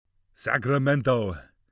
Here’s a recording of Arnold Schwarzenegger saying “Sacramento”.